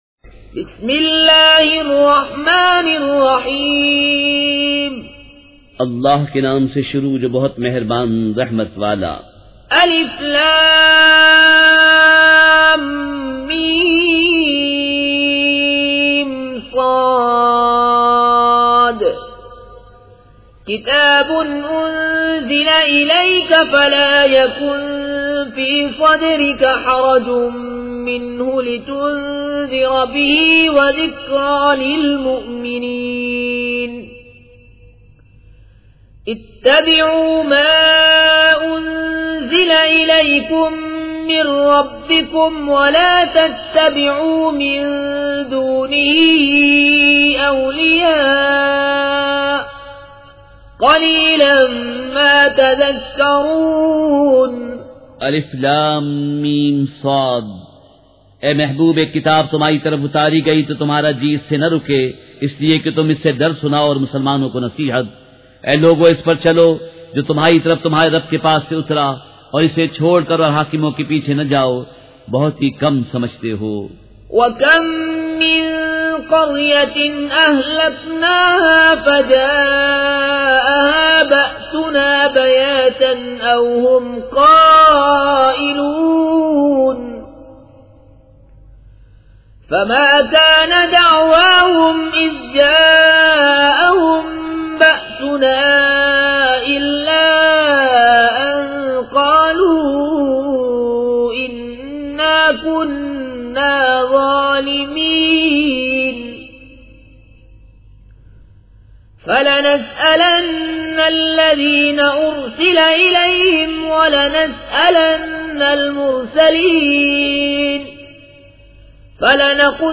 سورۃ الاعراف مع ترجمہ کنزالایمان ZiaeTaiba Audio میڈیا کی معلومات نام سورۃ الاعراف مع ترجمہ کنزالایمان موضوع تلاوت آواز دیگر زبان عربی کل نتائج 3301 قسم آڈیو ڈاؤن لوڈ MP 3 ڈاؤن لوڈ MP 4 متعلقہ تجویزوآراء